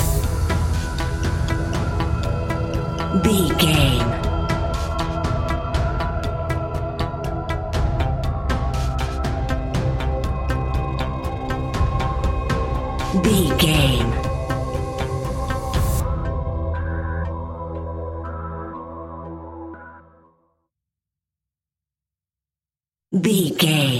Aeolian/Minor
ominous
eerie
synthesizer
drum machine
ticking
electronic music